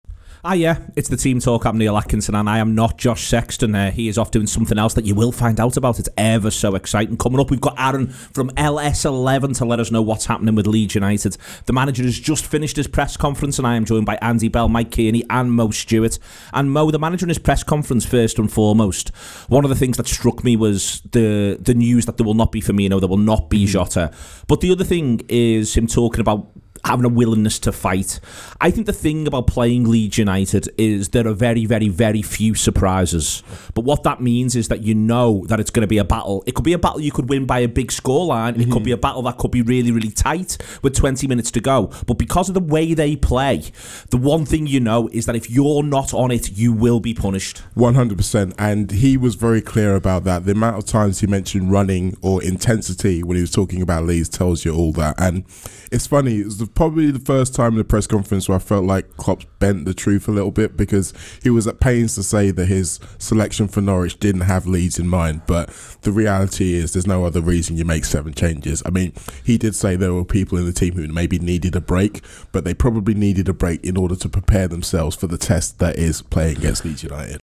Below is a clip from the show – subscribe for more on Klopp’s Liverpool v Leeds press conference…